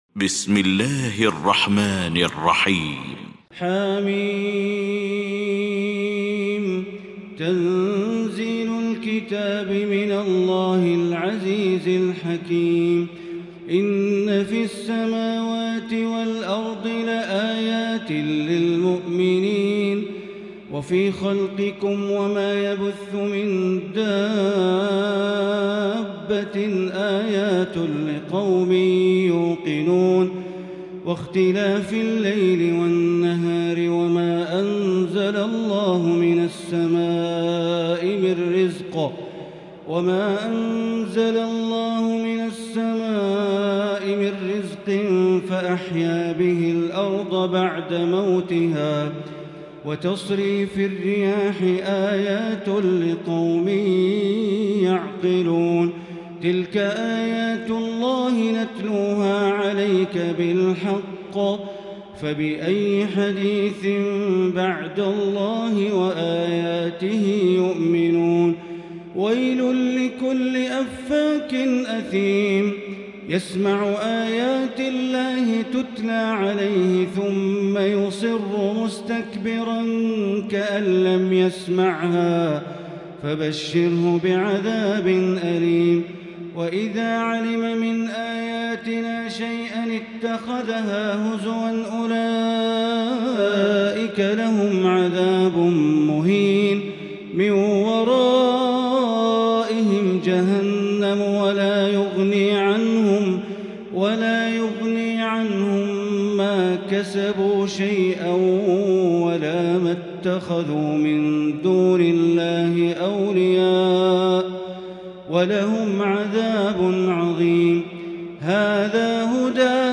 المكان: المسجد الحرام الشيخ: معالي الشيخ أ.د. بندر بليلة معالي الشيخ أ.د. بندر بليلة الجاثية